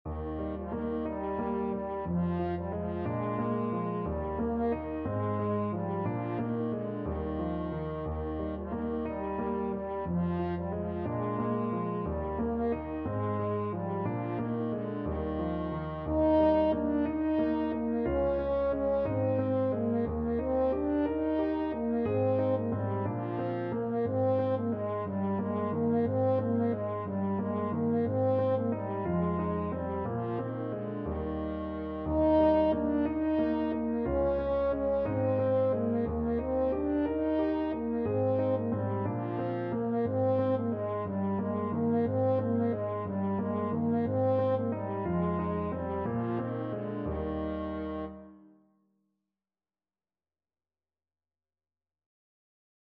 French Horn
It started life as a jig with Irish roots, whose first appearance seems to be in a collection published in London in 1661 entitled 'An Antidote Against Melancholy', where it is set to the words 'There was an old man of Waltham Cross'."
Eb major (Sounding Pitch) Bb major (French Horn in F) (View more Eb major Music for French Horn )
6/8 (View more 6/8 Music)
Traditional (View more Traditional French Horn Music)